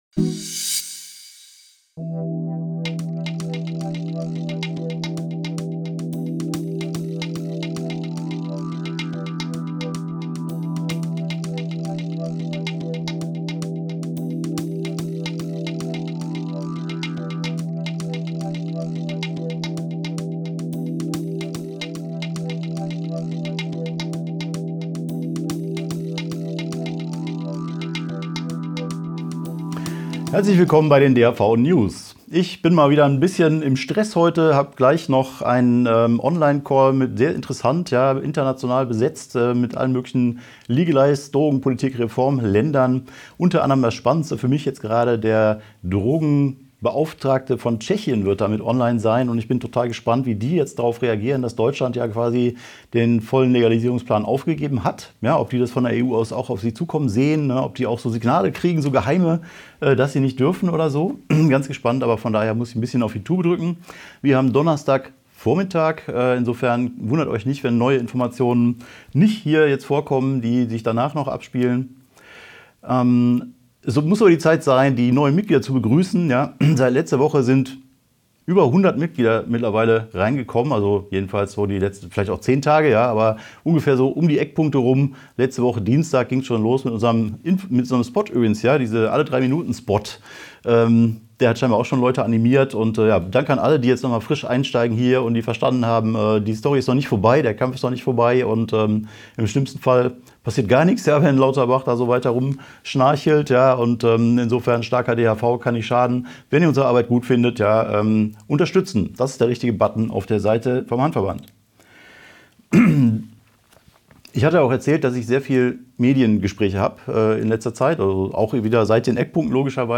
DHV-News #377 Die Hanfverband-Videonews vom 21.04.2023 Die Tonspur der Sendung steht als Audio-Podcast am Ende dieser Nachricht zum downloaden oder direkt hören zur Verfügung.